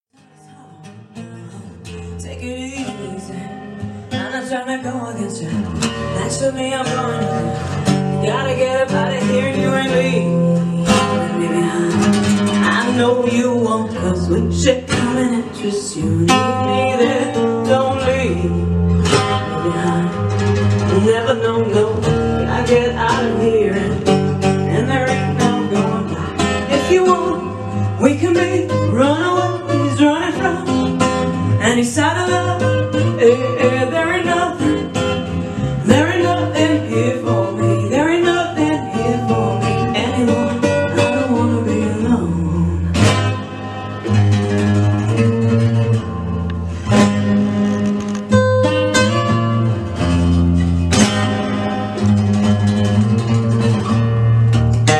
Gesang-Samples